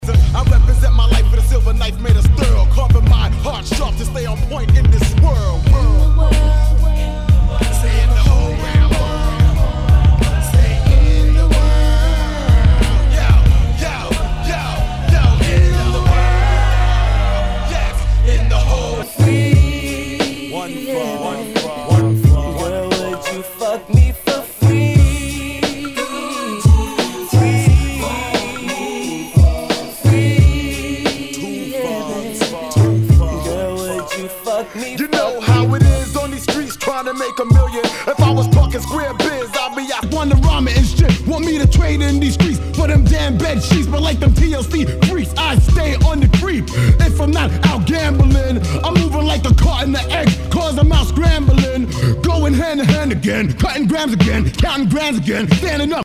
HIPHOP/R&B